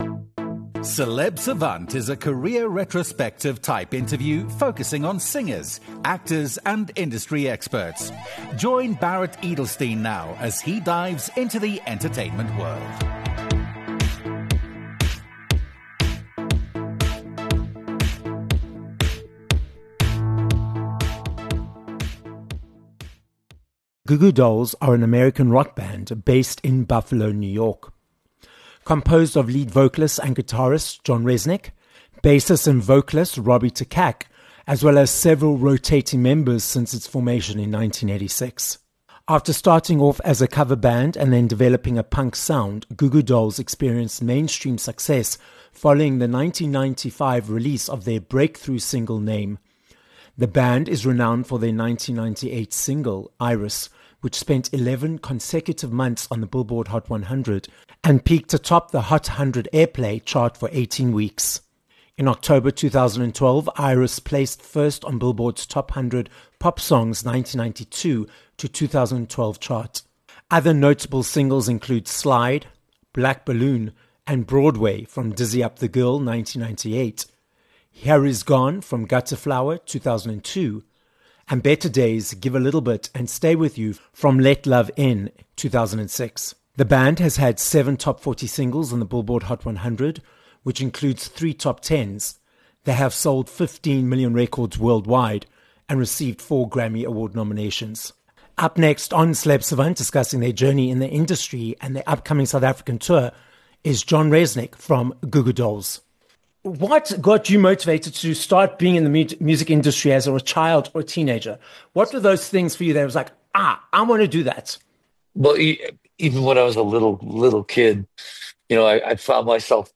Goo Goo Dolls - John Rzeznik from The Grammy Award-nominated American punk band - joins us on this episode of Celeb Savant. With 15 million records sold worldwide, John tells how they chose the band's name, why they keep creating music after all these decades, and about their upcoming South African tour.